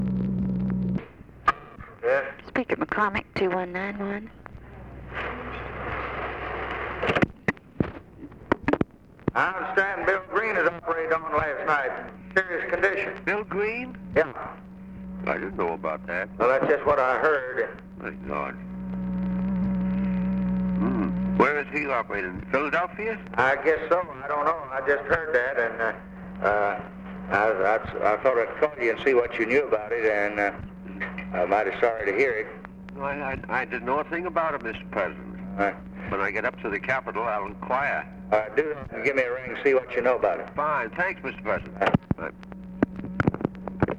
Conversation with JOHN MCCORMACK, December 9, 1963
Secret White House Tapes